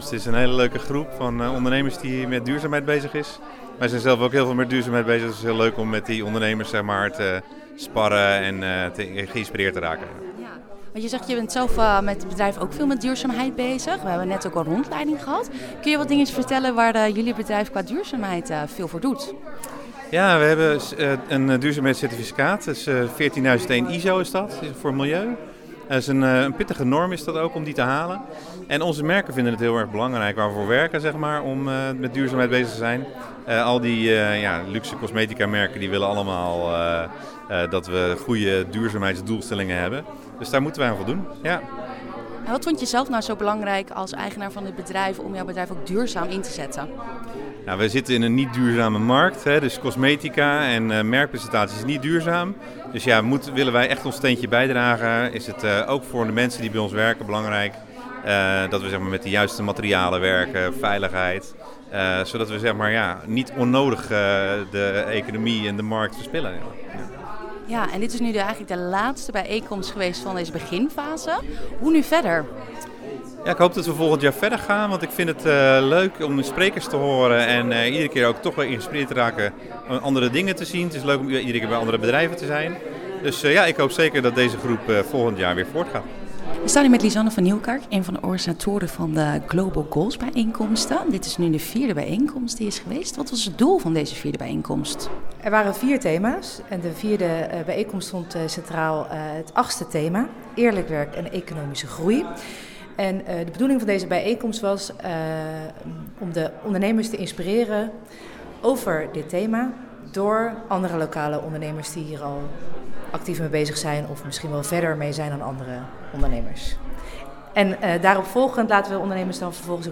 Verslaggever
ging langs bij de bijeenkomst en sprak met ondernemer